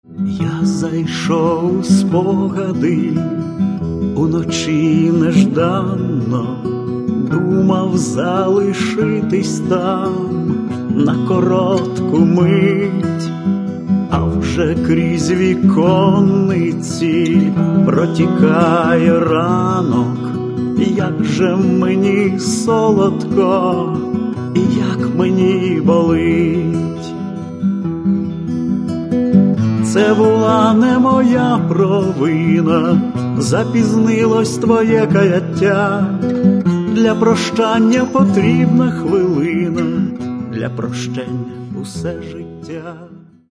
Каталог -> Інше -> Барди
Поезія, голос, гітара – от, власне, і майже все.